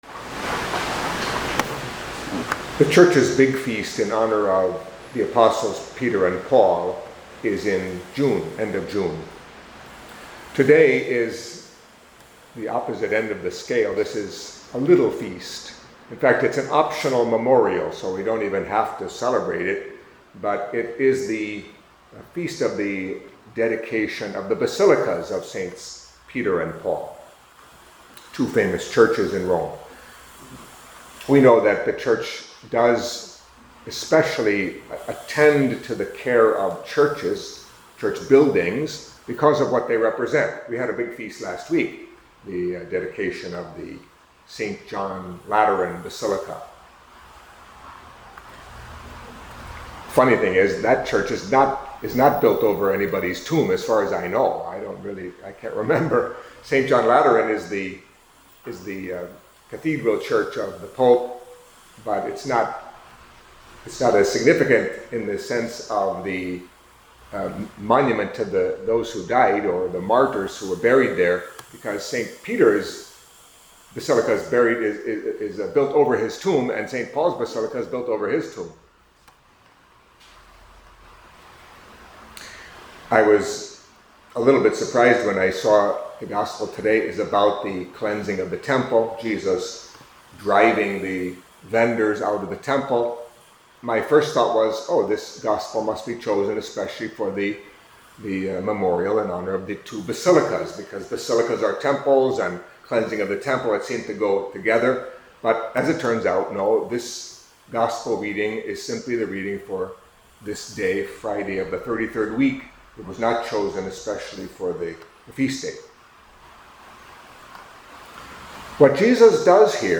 Catholic Mass homily for Friday of the Thirty-Third Week of Ordinary Time